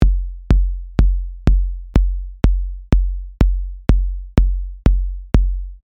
kick [techno]
Не могу повторить, мб кто подскажет? Слышу, что у оригинала клик более явный и звонкий.